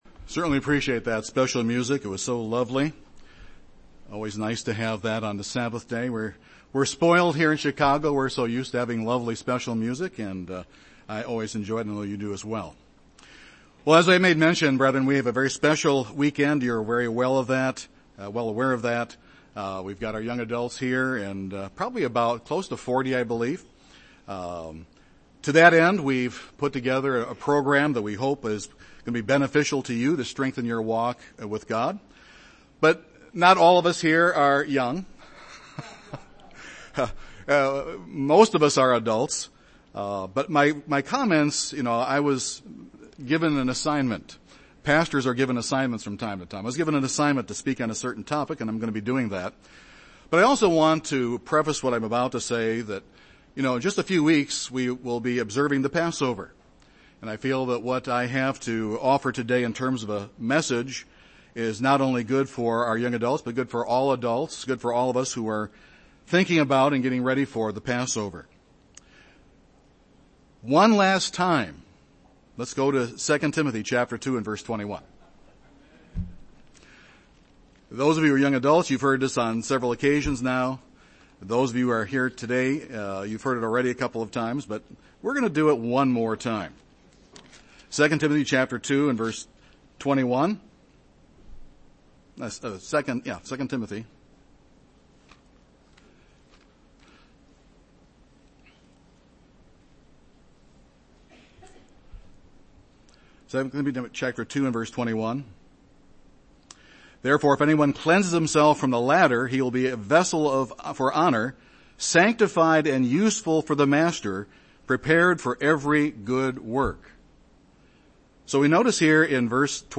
God is love, therefore God's children need to be loving people. This sermon looks at practical application of 1 Corinthians 13, the "love chapter."